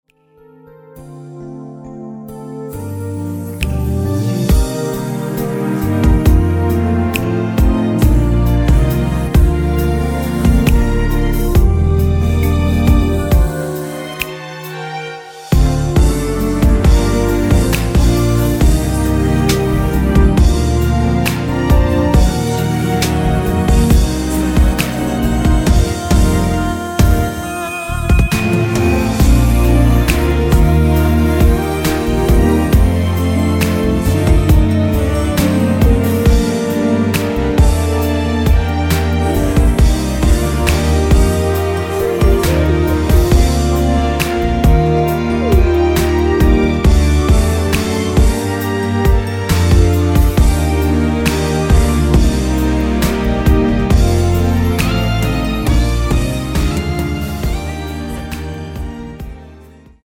Bb
앞부분30초, 뒷부분30초씩 편집해서 올려 드리고 있습니다.